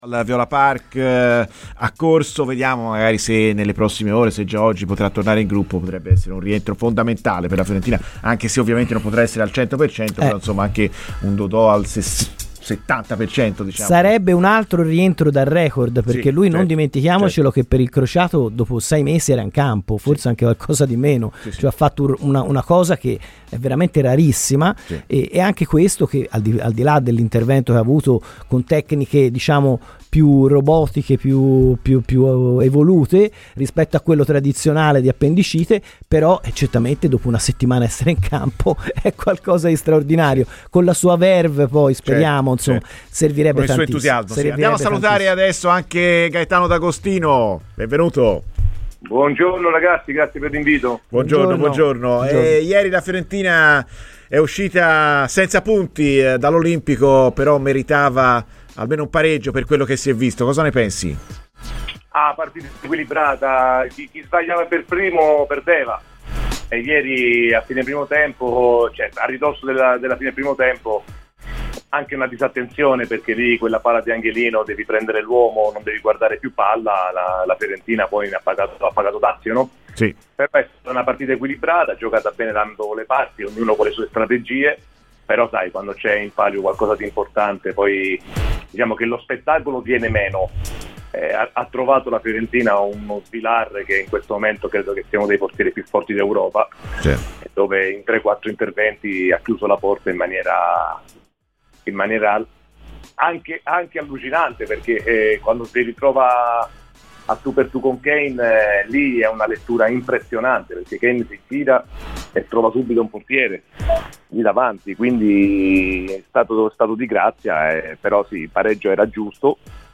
L'ex centrocampista della Fiorentina Gaetano D'Agostino ha parlato oggi a Radio Firenzeviola, durante 'Viola amore mio', all'indomani della partita persa dai viola a Roma: "È stata una bella partita, molto equilibrata.